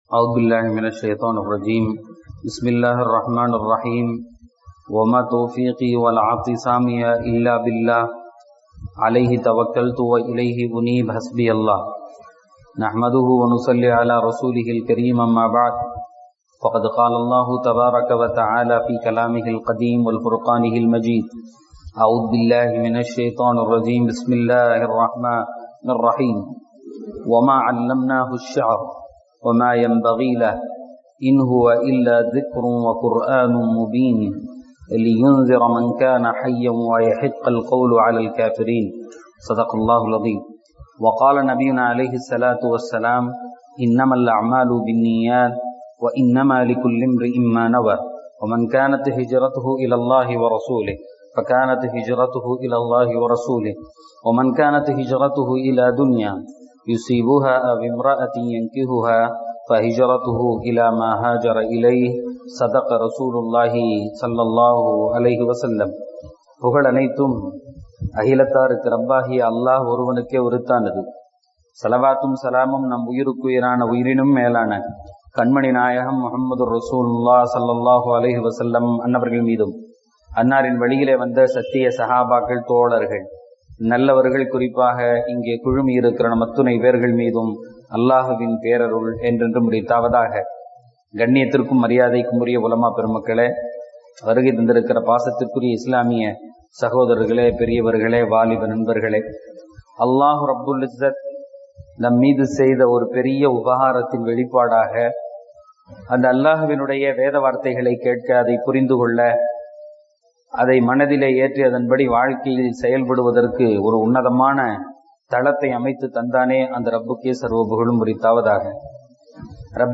யாஸீன் விளக்கவுரை: ஆயத்: 69-70 (03-Sep-2017) 36:69.